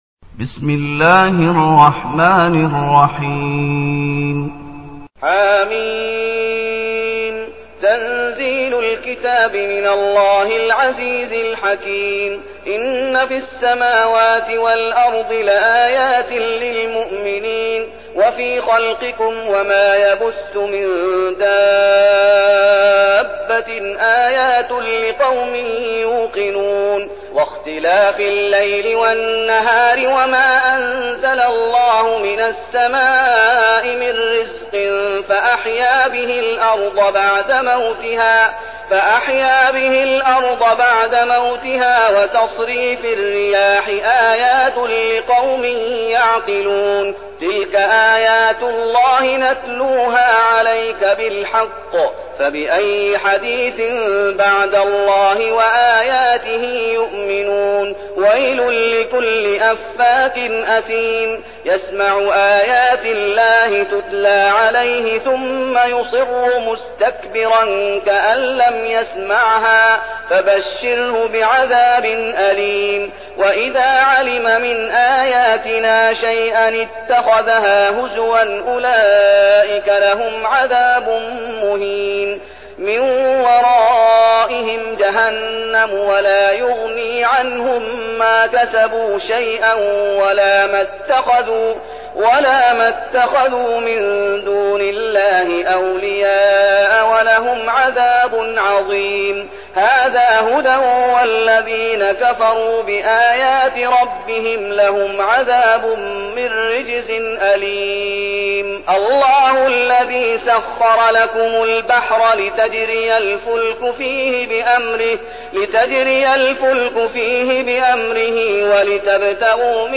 المكان: المسجد النبوي الشيخ: فضيلة الشيخ محمد أيوب فضيلة الشيخ محمد أيوب الجاثية